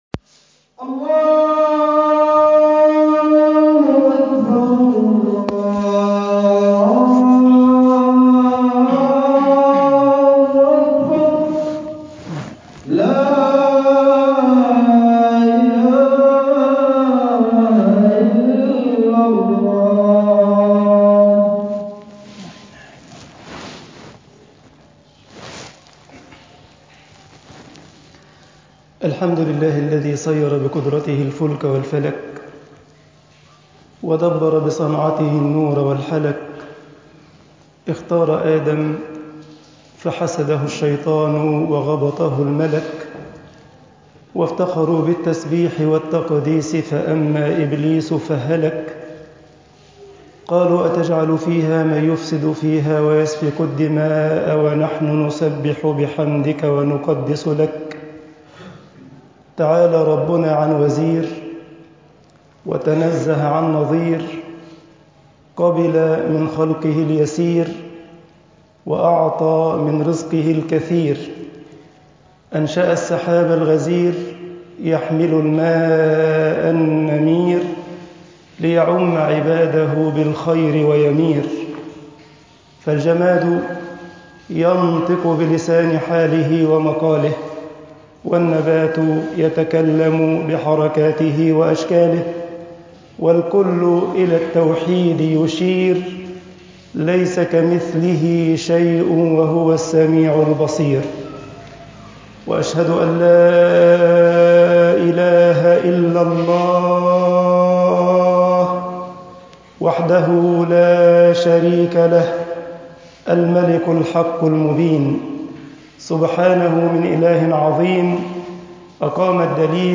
خطب الجمعة - مصر الاستجابة بين الرهبة والرغبة